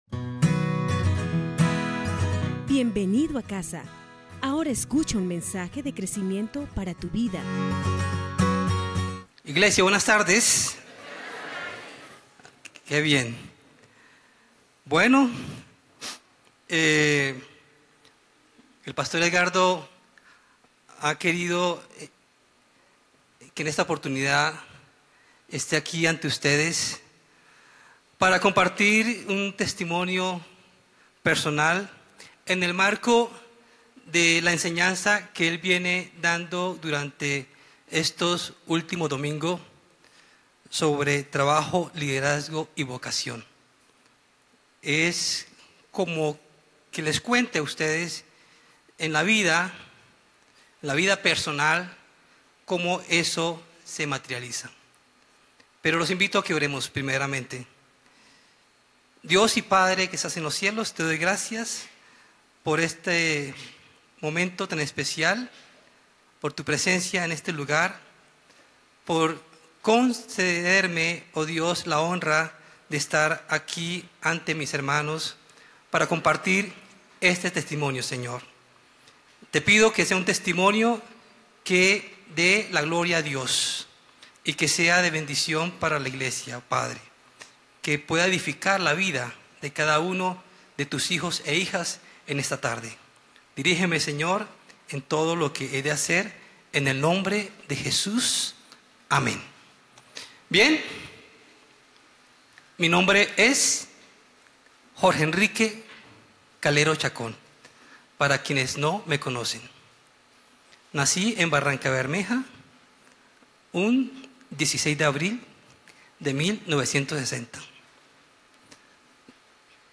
Lección 1: Trabajo, liderazgo y vocación - Parte 3 MP3